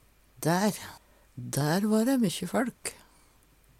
Uvdal og Veggli har ofte æ-lyd der dei andre bygdene har e-lyd